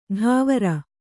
♪ ḍhāvara